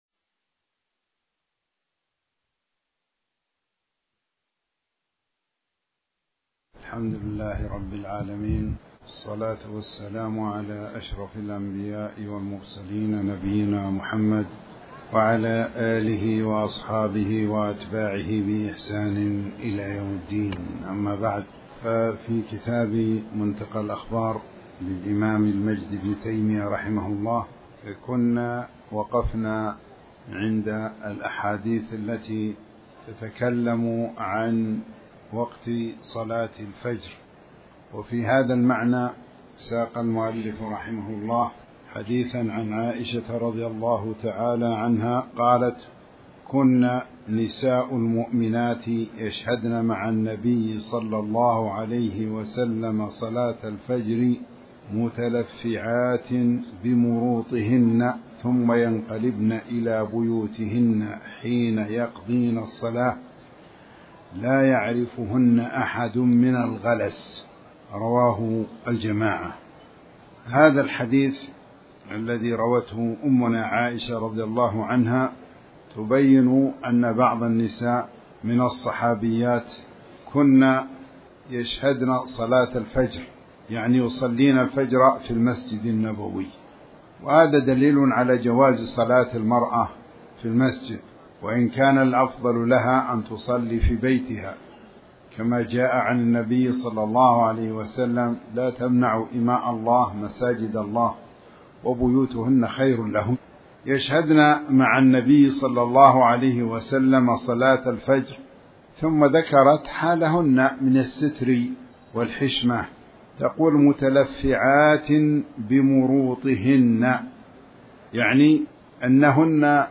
تاريخ النشر ٨ محرم ١٤٤٠ هـ المكان: المسجد الحرام الشيخ